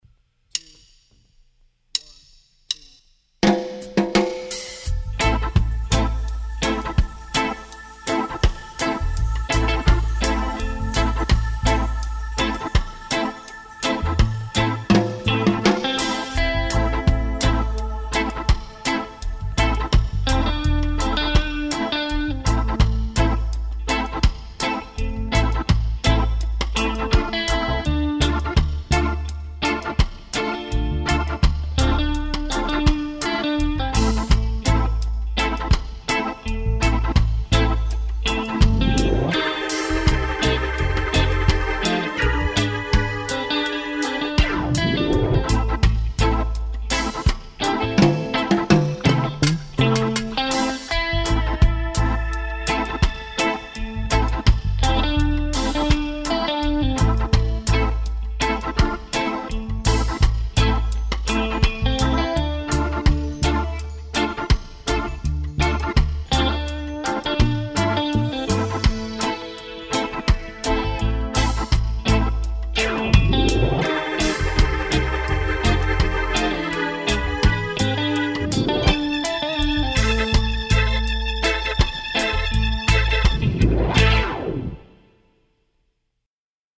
"Reggae"